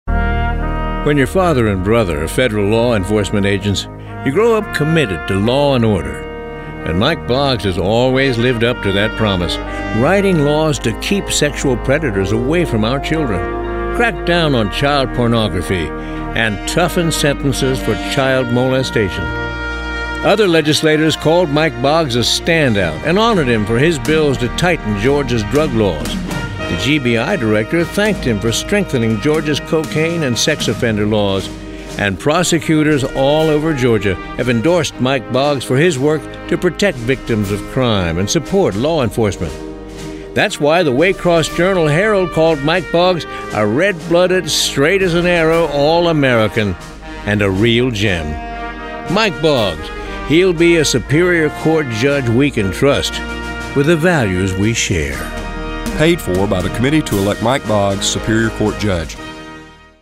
Download File: Boggs Radio Ad 2 | United States Senate Committee on the Judiciary